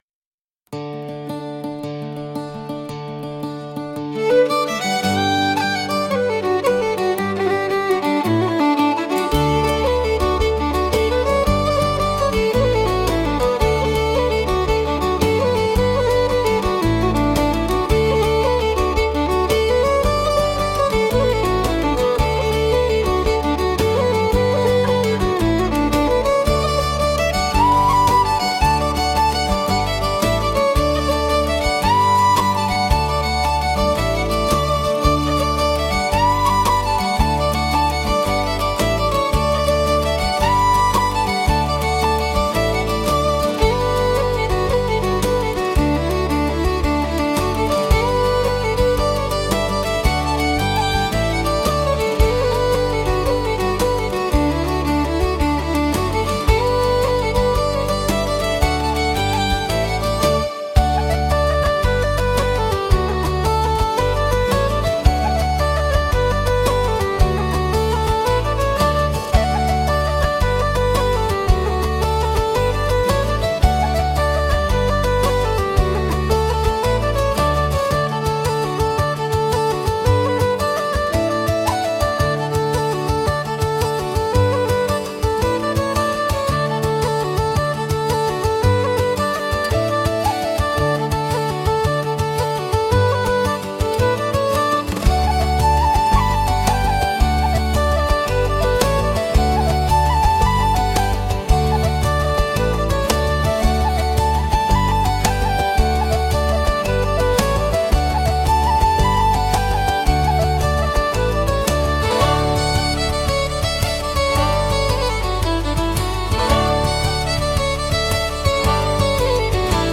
calm instrumental playlist